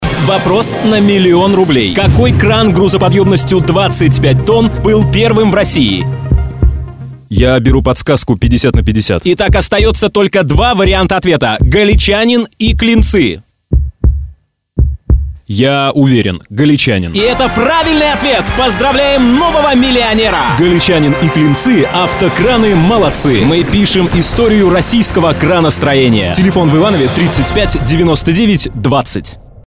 avtokran.mp3